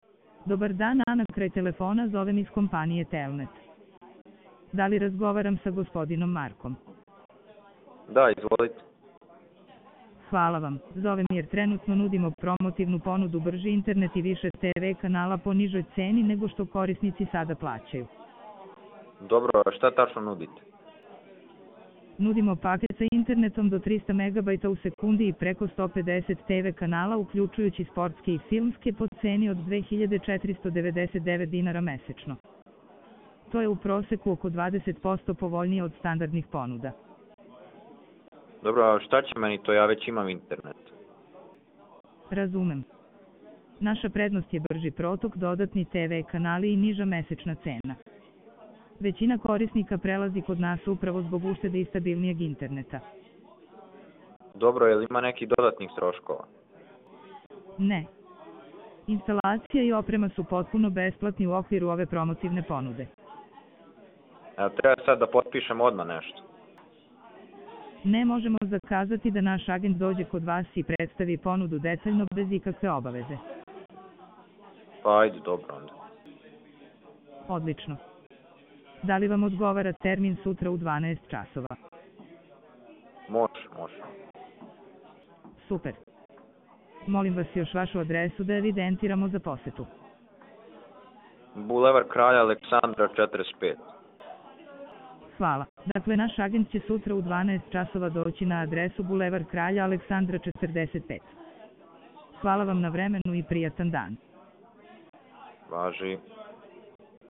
Ovo su demonstracije stvarnih scenarija u kojima AI agent razgovara kao pravi operater.